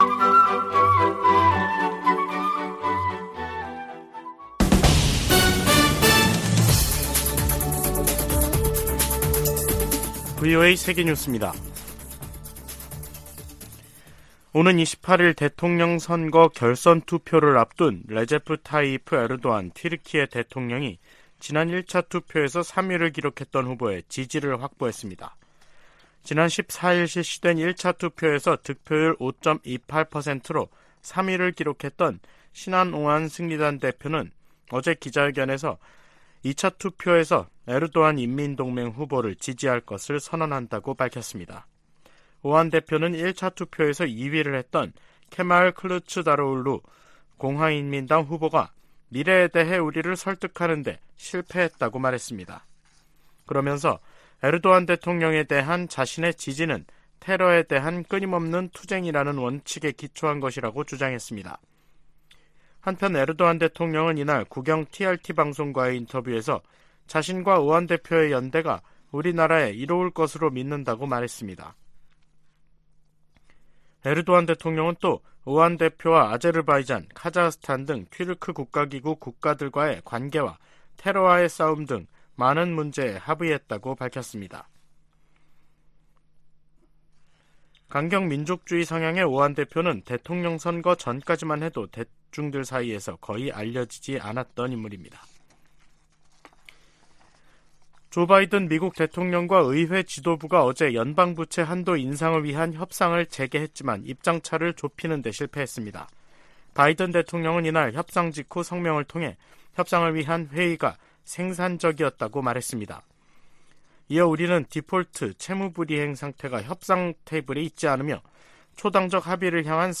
VOA 한국어 간판 뉴스 프로그램 '뉴스 투데이', 2023년 5월 23일 2부 방송입니다. 조 바이든 미국 대통령은 일본 히로시마 G7 정상회의로 미한일 3자 협력이 새 차원으로 격상됐다고 말했습니다. G7을 계기로 미한일 공조가 강화되면서 북한, 중국, 러시아의 외교, 안보, 경제의 밀착이 구체화 될 것이라고 전문가들이 내다보고 있습니다. 한국과 유럽연합(EU) 정상이 북한의 도발 행위를 강력히 규탄하며 비핵화 대화에 복귀할 것을 촉구했습니다.